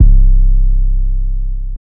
{808} MurdaBass.wav